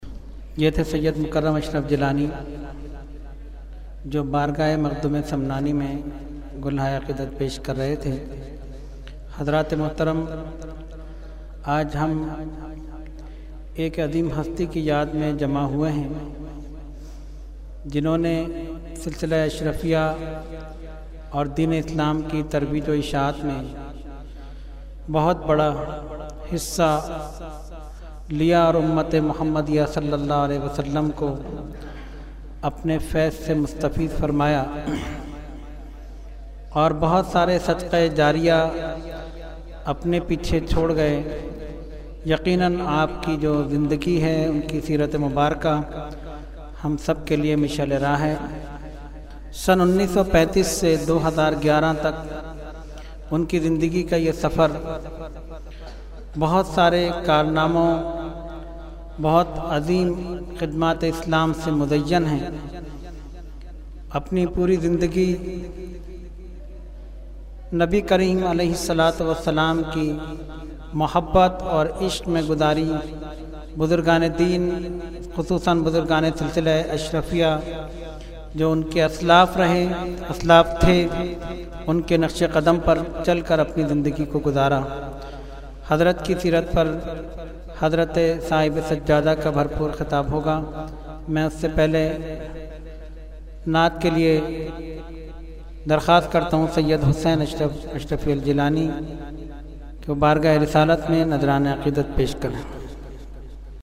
Category : Speech
Taziyati Program